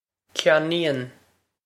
Pronunciation for how to say
kyan-een she
This is an approximate phonetic pronunciation of the phrase.